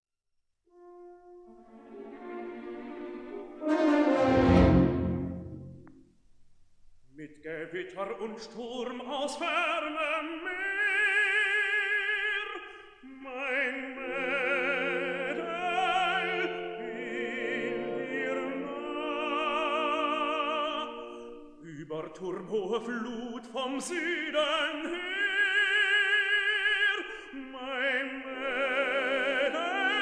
Stereo recording made in Berlin